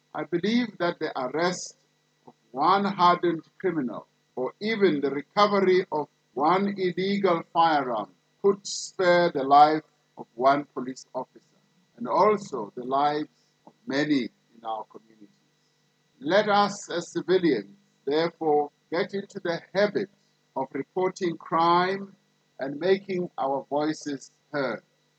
During the annual South African Police Service Commemoration Day, Ramaphosa said more would also be done to bring to book those who are responsible for killing police officers.